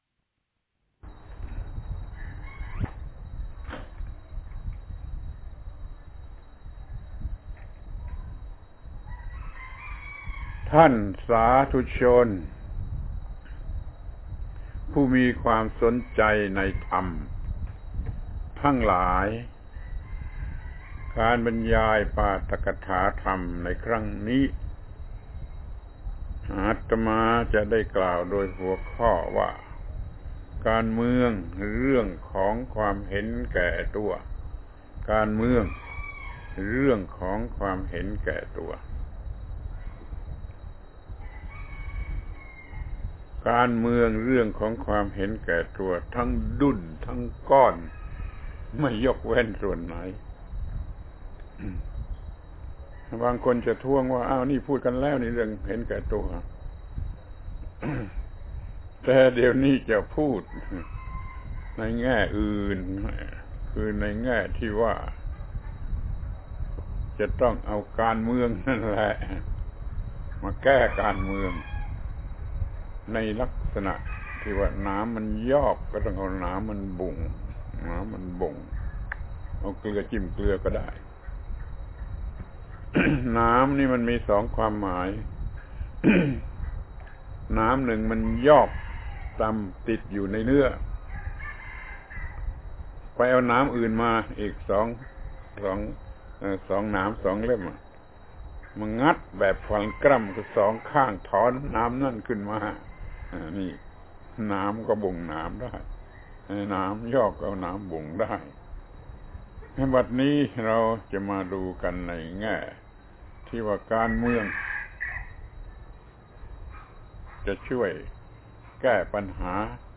ปาฐกถาธรรมทางวิทยุ ชุด การเมือง การเมืองเรื่องของความเห็นแก่ตัว